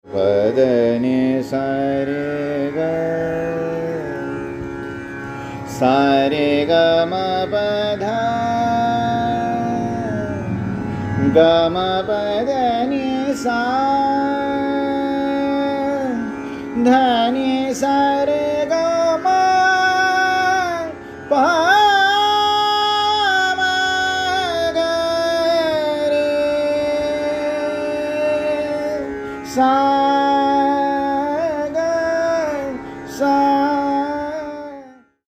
a balanced yet bipolar mood, with each side of the scale presenting contrasting colours, and Sa acting as a natural ‘pivot’ between them.
Aroha: SrgmPDNS
Avroh: SNDPmgrS
[alap, e.g. 0:00] P(N)DN Srg, SrgmPD(Pm), gmPD(S)NS, DNSrgm(g), gmP(mP)mgr, S(rgr) g(rS), S(rSrgrN), N(SNSND), D(NSNDP), PD(Pm), mP(mg), gm rg, SrgmP (N)DNS…
• Tanpura: Sa–Pa